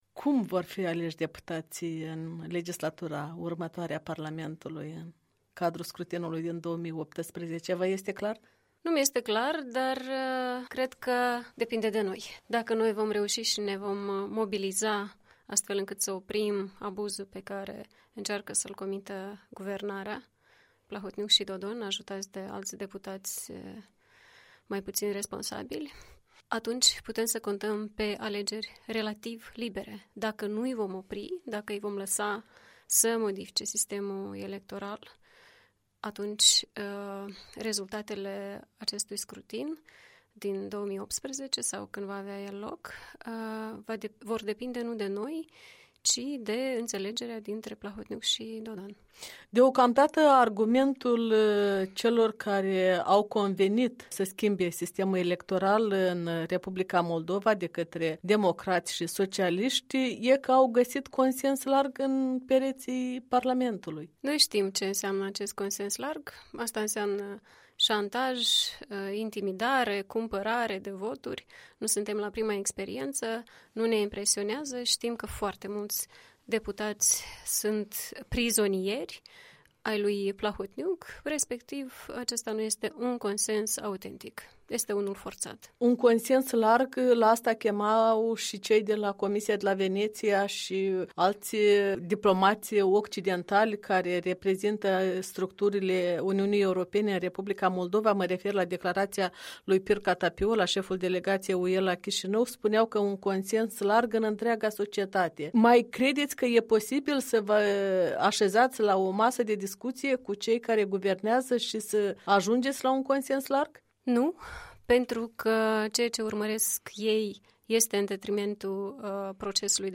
Un interviu cu lidera Partidului Acțiune și Solidaritate (PAS).